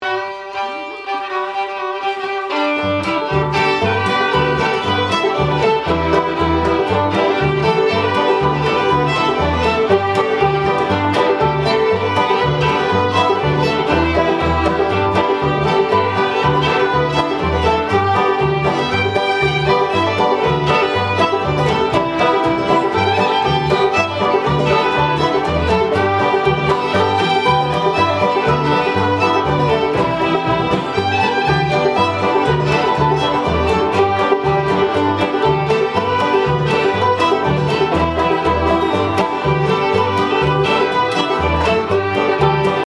~ Live acoustic music from the